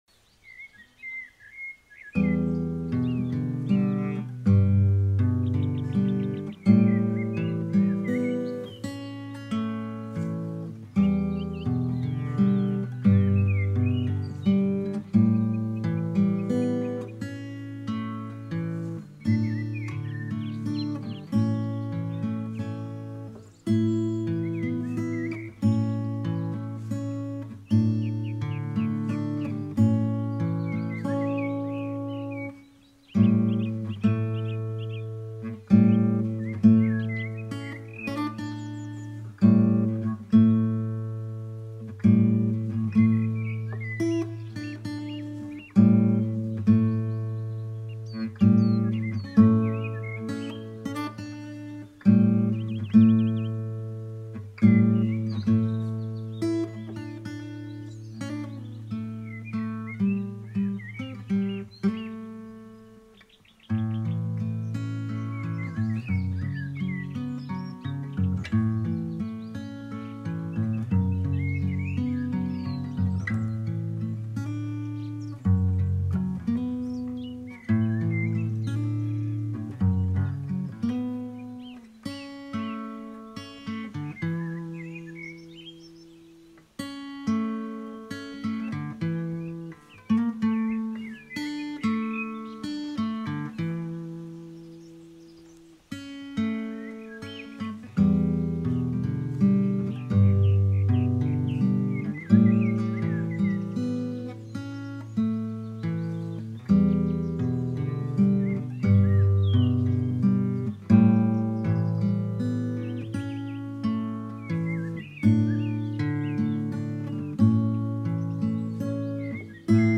Acoustic Guitar
Background Sounds